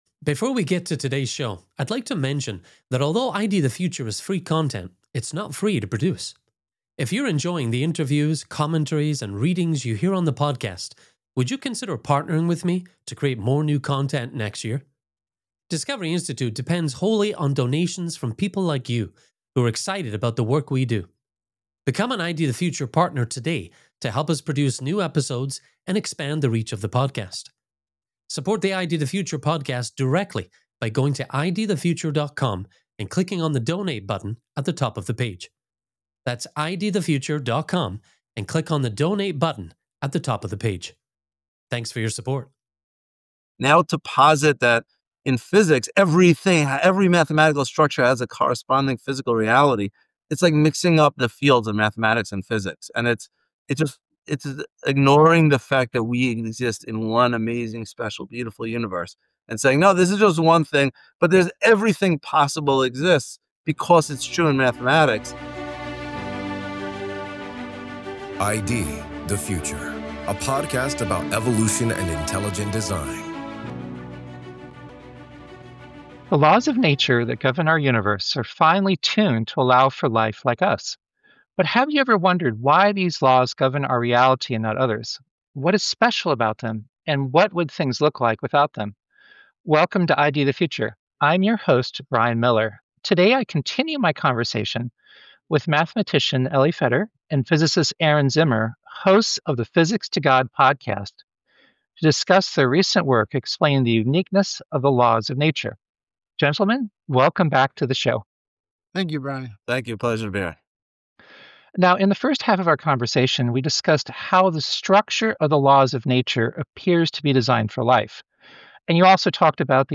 This half of the conversation tackles the attempts made by scientists to explain these life-friendly laws as the result of chance, not design. This is Part 2 of a two-part conversation.